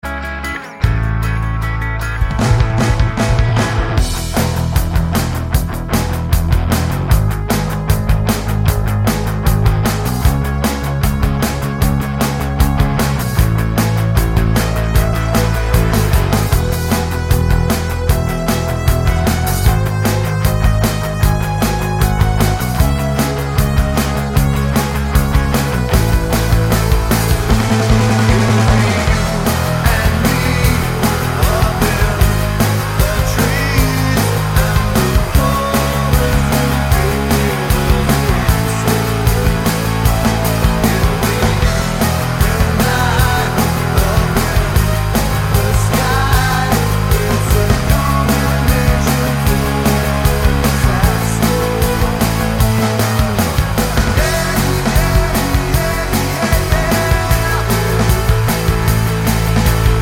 Live Version Rock 3:23 Buy £1.50